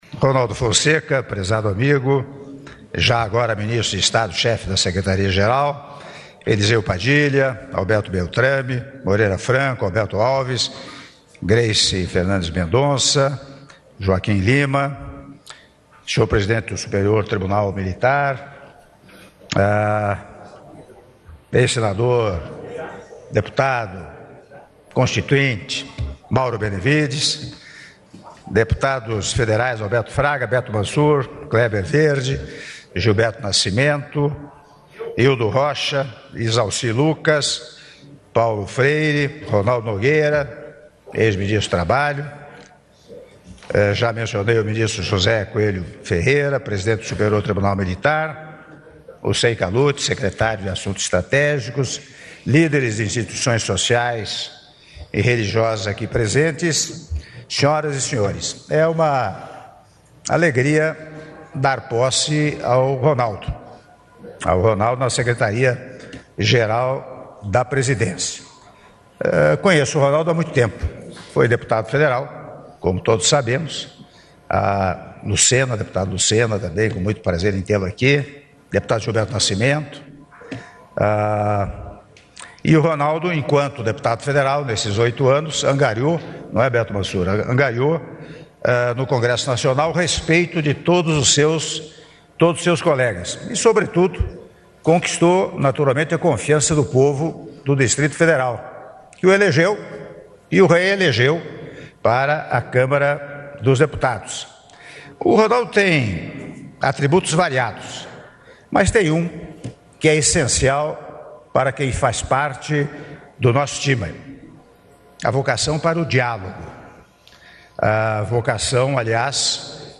Áudio do discurso do Presidente da República, Michel Temer, durante Cerimônia de Posse do Ministro de Estado Chefe da Secretaria-Geral da Presidência da República, Ronaldo Fonseca de Souza (06min04s) — Biblioteca